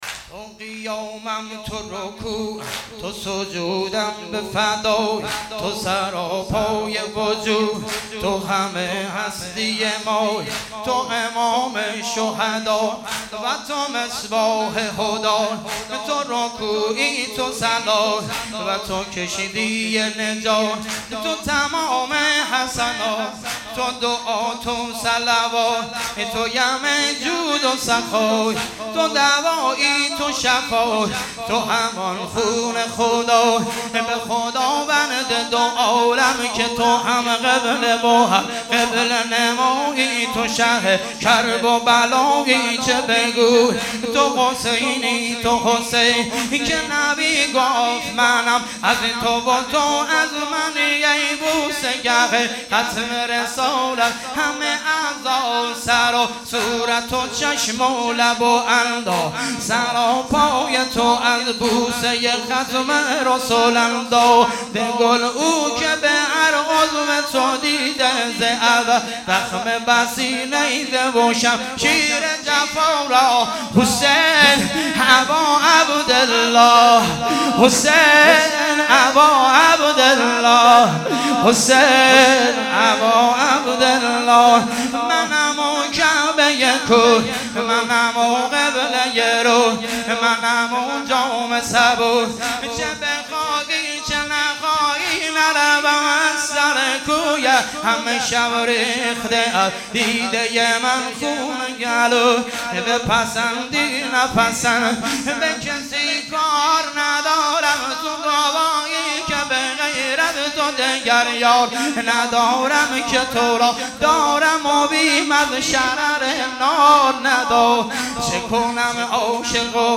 سبک واحد شلاقی - شب اول محرم97